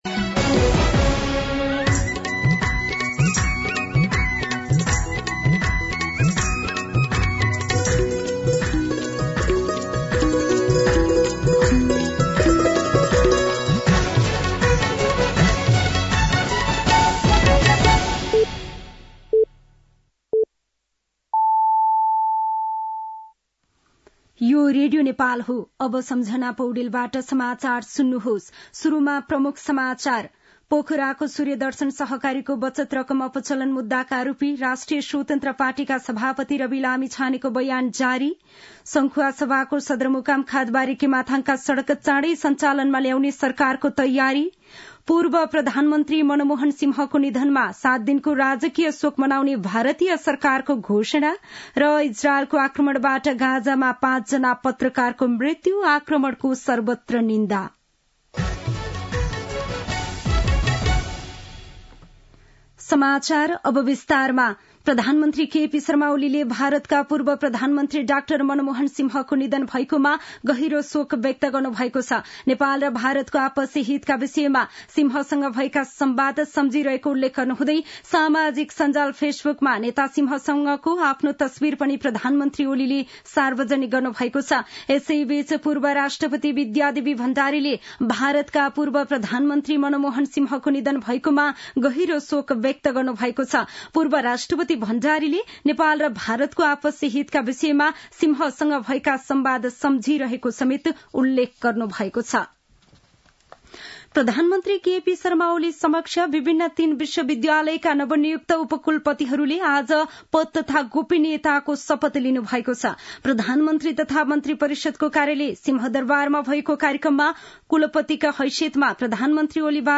दिउँसो ३ बजेको नेपाली समाचार : १३ पुष , २०८१
3-pm-nepali-news-2.mp3